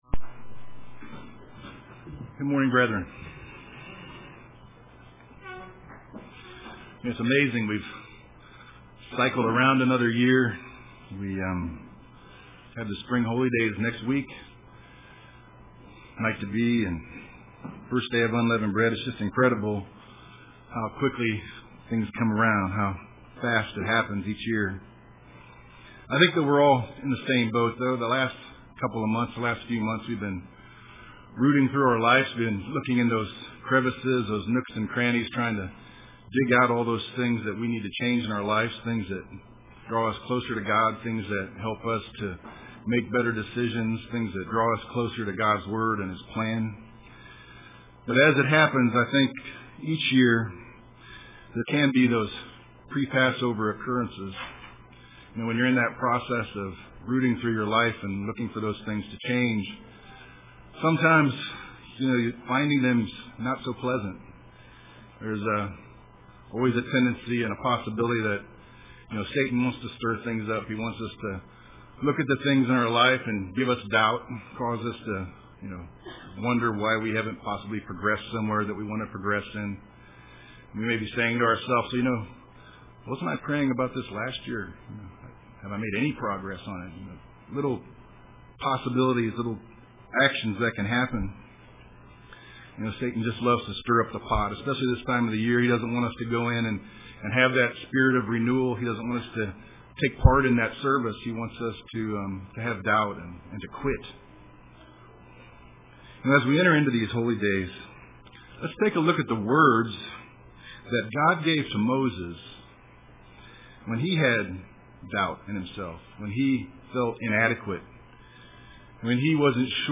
Print He Will UCG Sermon Studying the bible?